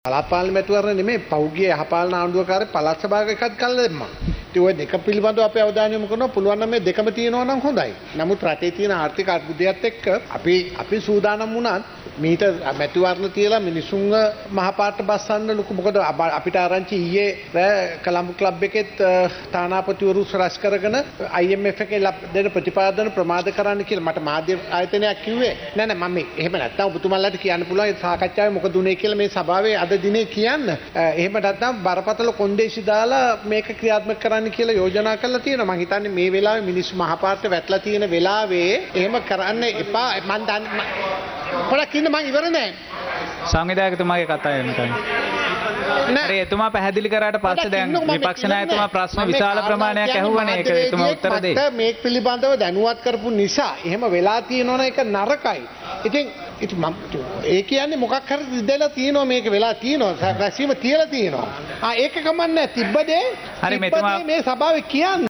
විපක්ෂ නායකවරයා දැක්වූ අදහස්වලට පිළිතුරු ලබා දුන් ආණ්ඩු පක්ෂයේ ප්‍රධාන සංවිධායක ප්‍රසන්න රණතුංග මහතා කියා සිටියේ පසුගිය යහපාලන කාලසීමාව තුළදි පළාත් පාලන මැතිවරණය පමණක් නොව පළාත් සභා මැතිවරණයද කල් තැබූ බවයි.